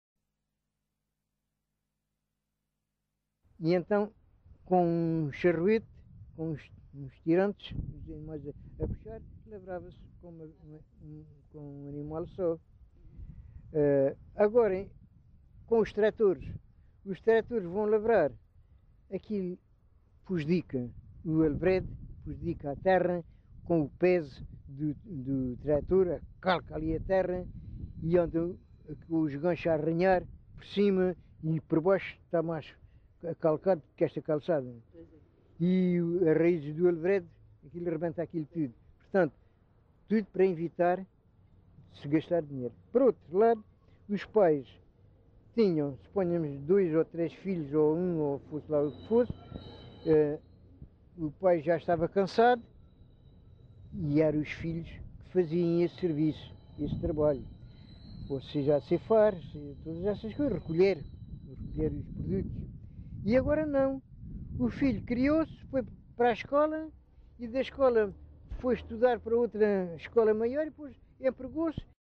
LocalidadePorches (Lagoa, Faro)